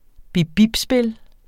Udtale [ bibˈbibˌspel ]